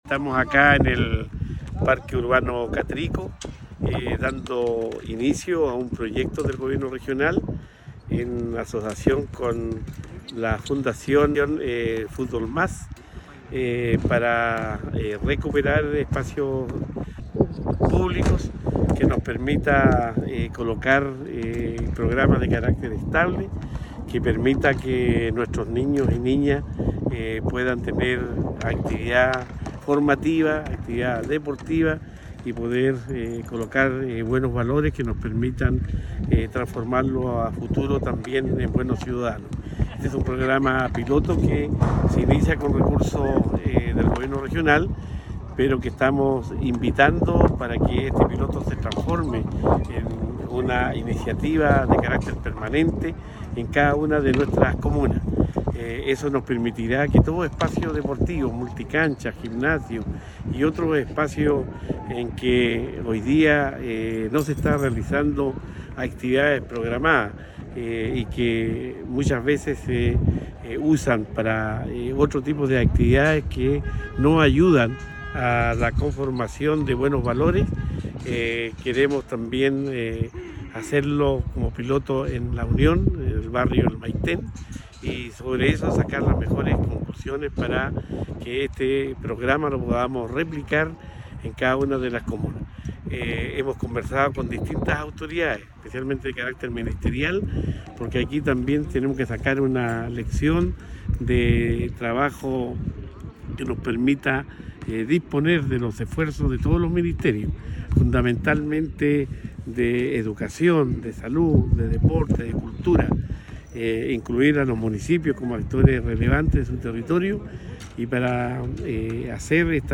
Cuña_Gobernador-Regional_Plan-de-Recuperación-de-Espacios-Públicos.mp3